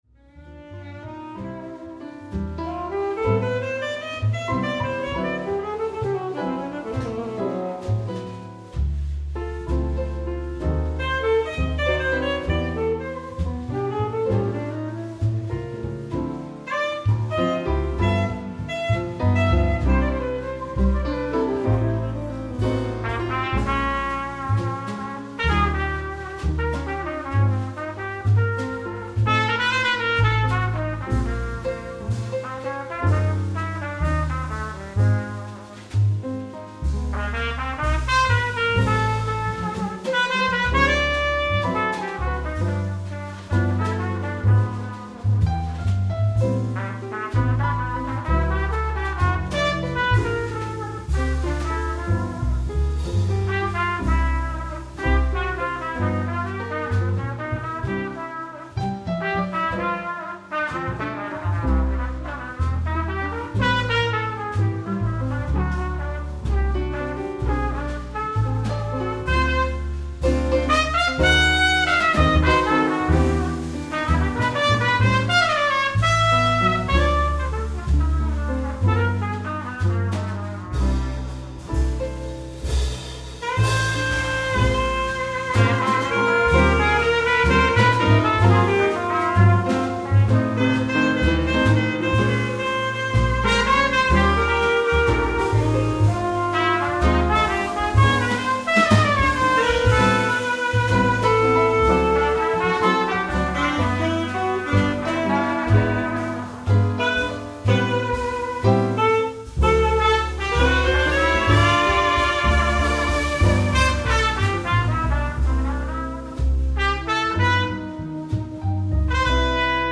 Opgenomen op 13 en 14 februari 1995 te Groningen.